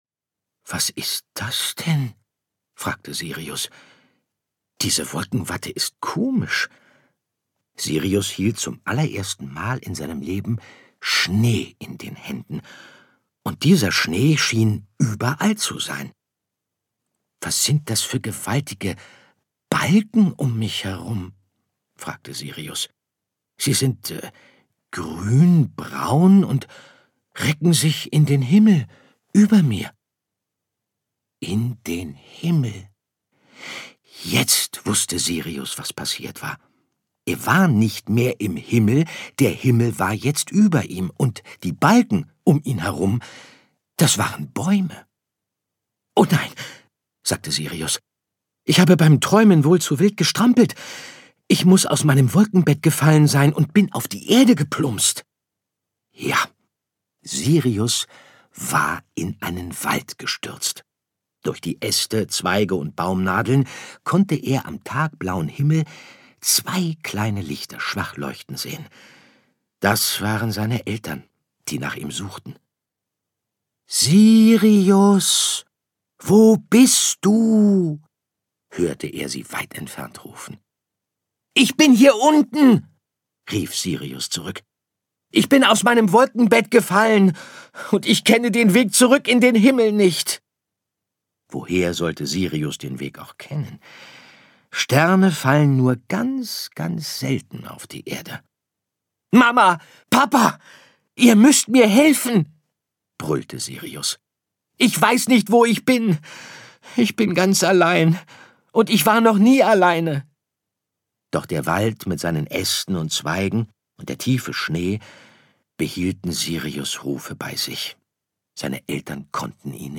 Interpret: Andreas Fröhlich
Mit Andreas Fröhlichs warmer und einfühlsamer Stimme wird jede Geschichte zum Fest.
Dazu noch die musikalischen Episoden, die einfach passend sind.